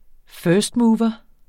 Udtale [ ˈfœːsdˌmuːvʌ ]